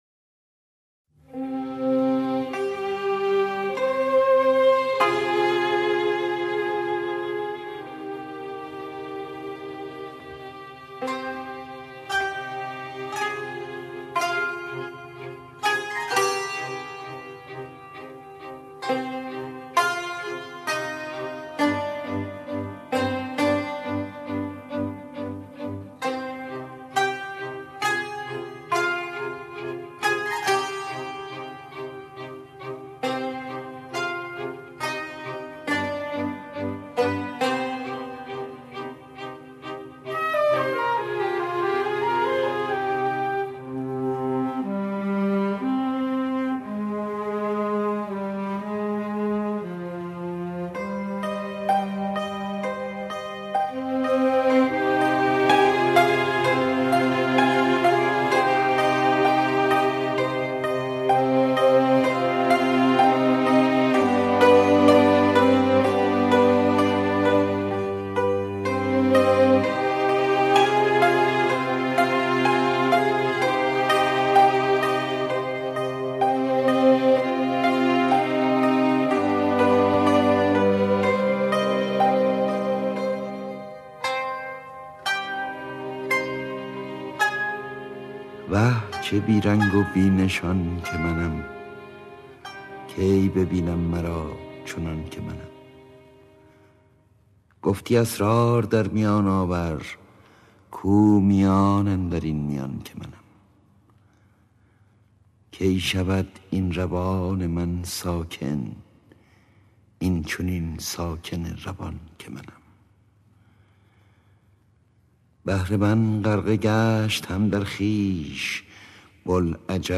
صدای: احمد شاملو
موسیقی متن:‌ فریدون شهبازیان
شعر خوانی احمد شاملو
شعرهای-مولوی-با-صدای-احمد-شاملو-راوی-حکایت-باقی.mp3